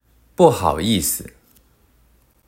「不（bù）」は四聲、「好（hǎo）」は三聲、「意（yì）」は四聲、「思（si）」は輕聲で「不好意思（bù hǎo yì si）」となります。
▼「不好意思（すみません）」発音サンプル
※発音サンプルは、中国語ネイティブの台湾人の方にお願いしました。